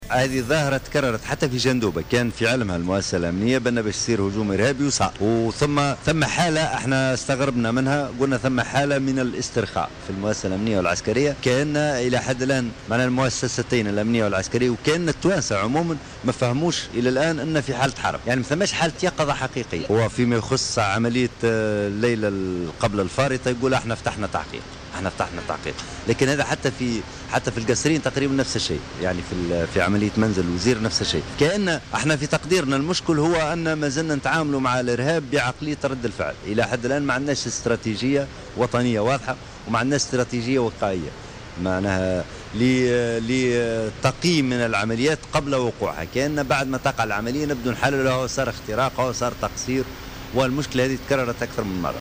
وأضاف الحامدي في تصريح لجوهرة أف أم أن المؤسستين الأمنية والعسكرية وحتى التونسيين لم يستوعبوا بعد بأننا في حالة حرب مع الإرهاب داعيا إلى ضرورة عقد المؤتمر الوطني لمقاومة الإرهاب لرسم استراتيجية وقائية لمكافحته بدل التعامل معه بعقلية رد الفعل.